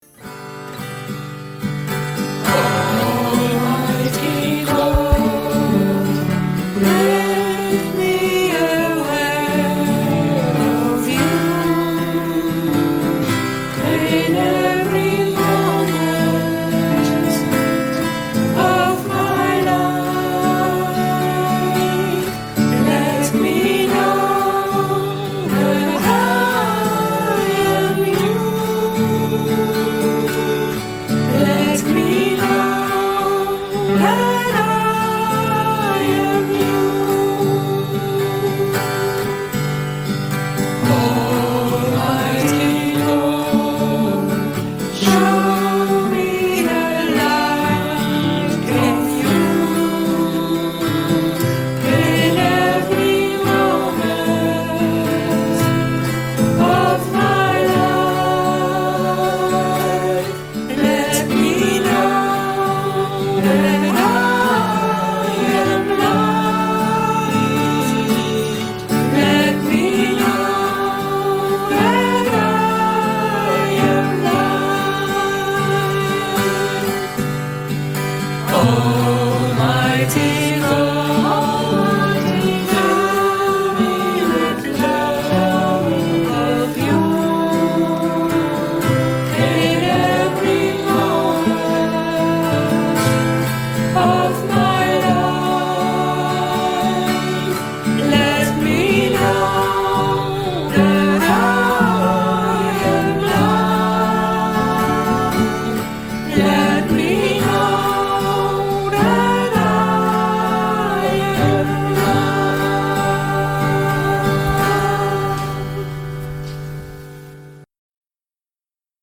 1. Devotional Songs
Major (Shankarabharanam / Bilawal)
8 Beat / Keherwa / Adi
Medium Slow
1 Pancham / C
5 Pancham / G